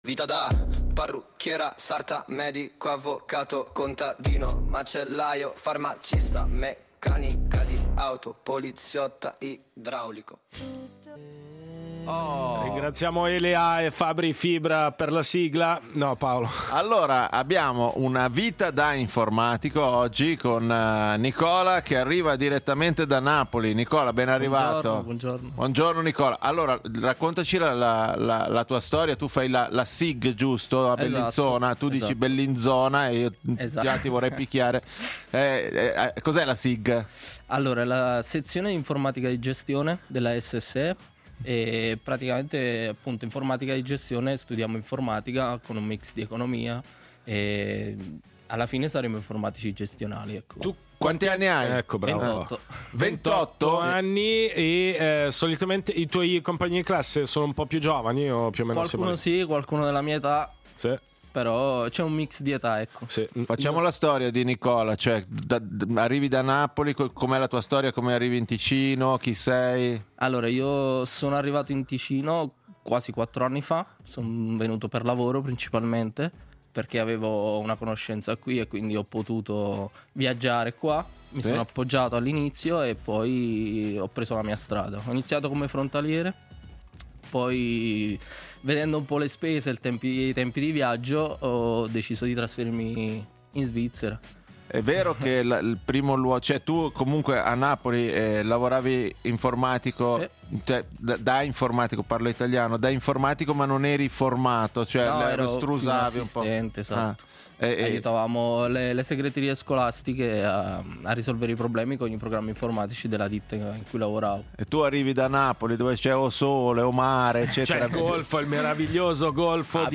Ospite in studio